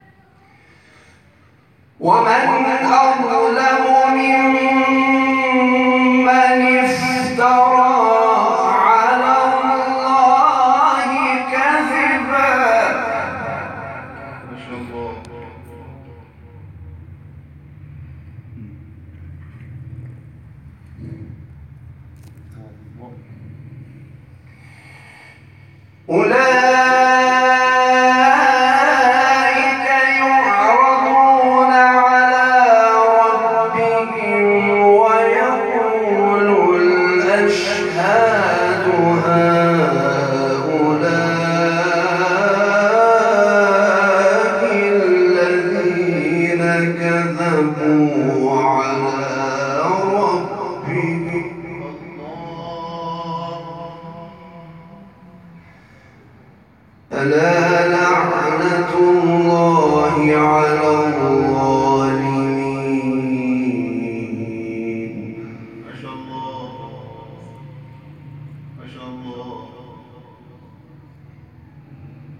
شبکه اجتماعی: مقاطع صوتی از قاریان ممتاز کشور را می‌شنوید.